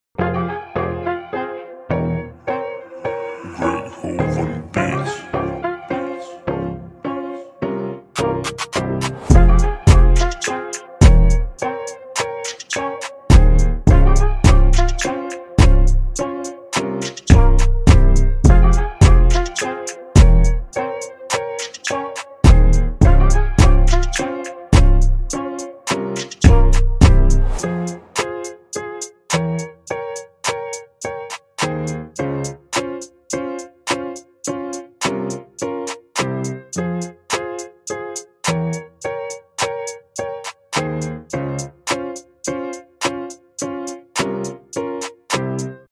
Hip-Hop / Rap / Freestyle Beat